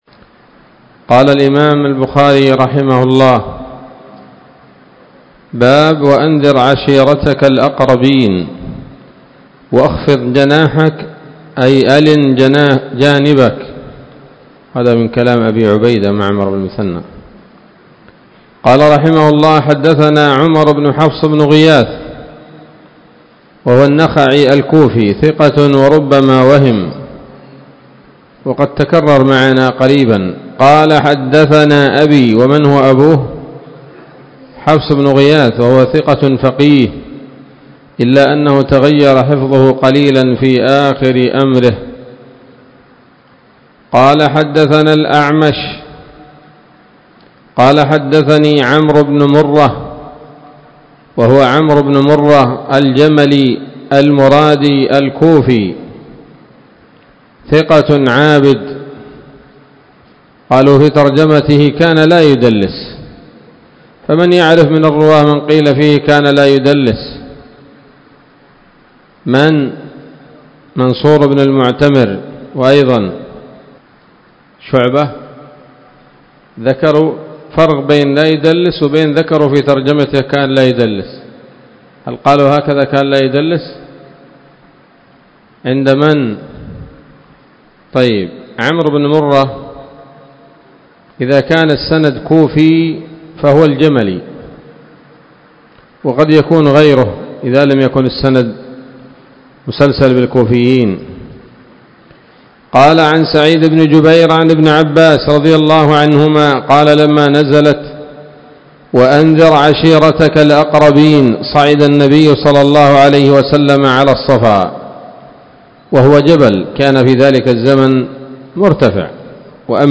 الدرس الثالث والتسعون بعد المائة من كتاب التفسير من صحيح الإمام البخاري